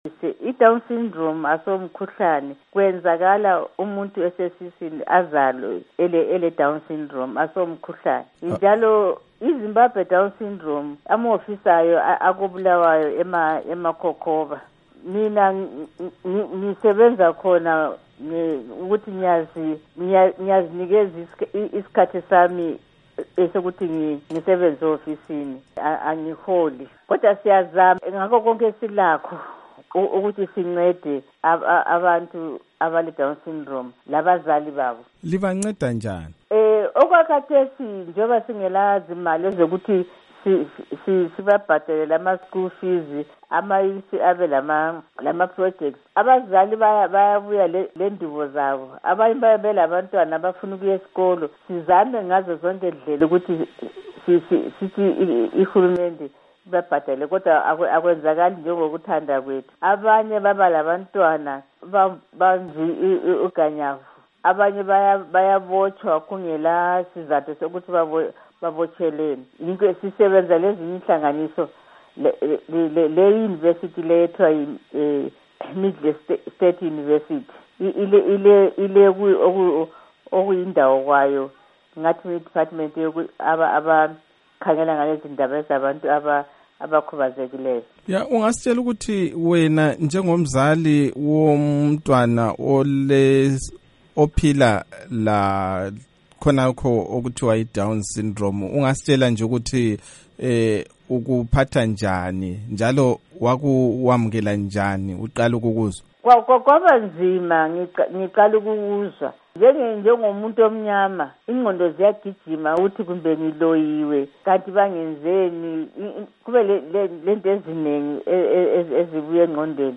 Ingxoxo ngodaba lwedowns syndrome